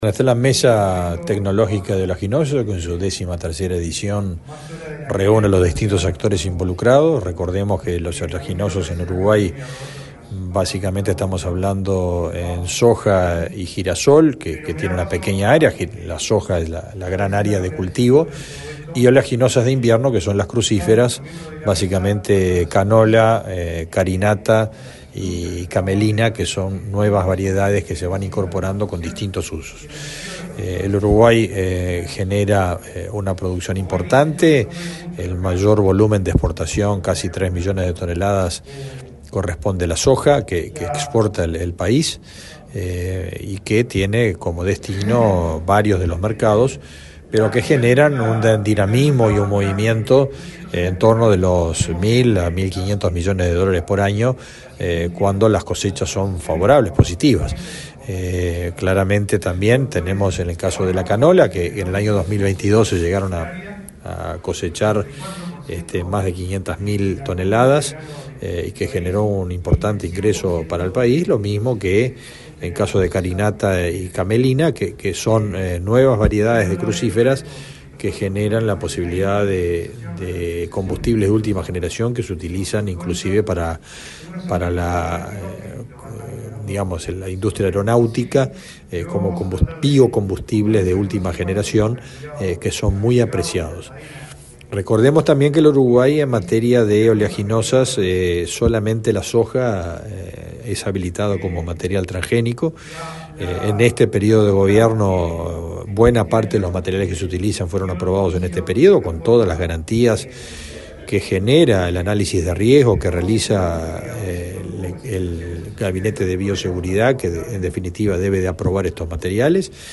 Declaraciones del ministro de Ganadería, Fernando Mattos
El ministro de Ganadería, Fernando Mattos, dialogó con la prensa, luego de participar de la apertura del 13.° encuentro nacional de la Mesa
El evento se realizó este miércoles 9 en el Laboratorio Tecnológico del Uruguay.